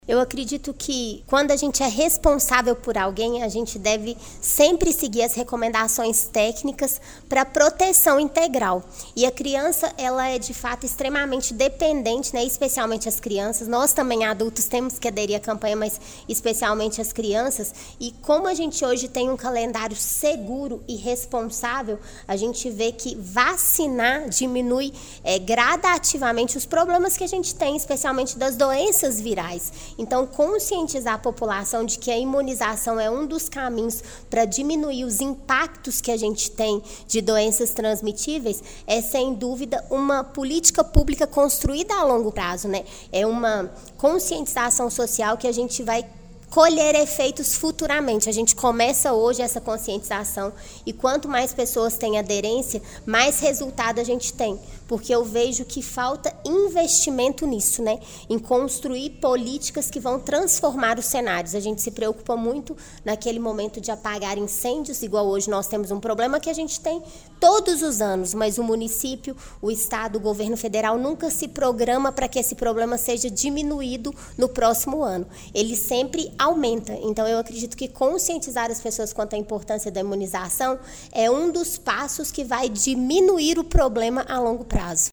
Durante a reunião da Câmara Municipal de Pará de Minas realizada na terça-feira (27), acompanhada pelo Portal GRNEWS, a vereadora Márcia Flávia Marzagão Albano (PSDB), que também é mãe, enfatizou a relevância da conscientização dos pais para a vacinação de crianças e a atualização do cartão de vacinas.